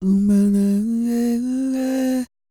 E-CROON 3036.wav